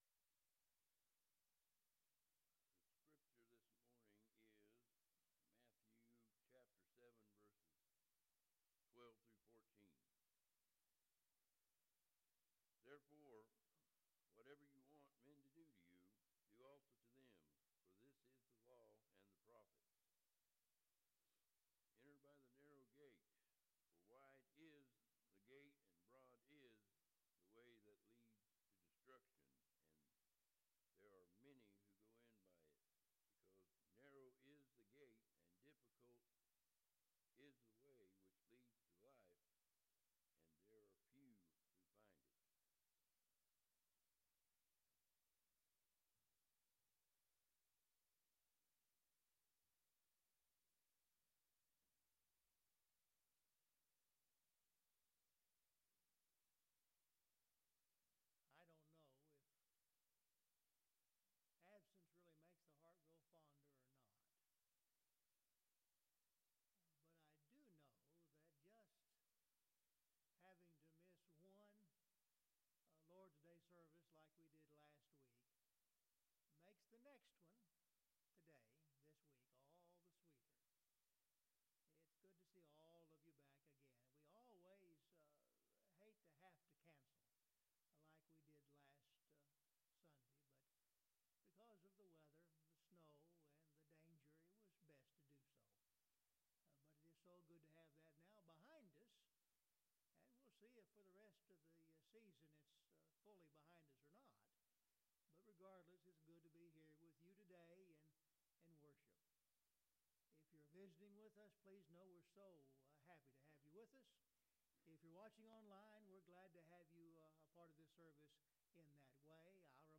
Luke 10:23-37 (The audio is weak until the 6:46 mark)